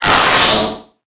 Cri de Fourbelin dans Pokémon Épée et Bouclier.